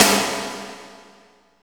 52.03 SNR.wav